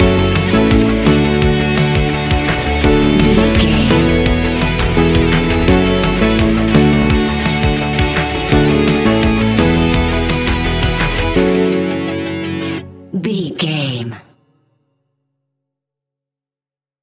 Ionian/Major
ambient
electronic
new age
chill out
downtempo
soundscape
synth
pads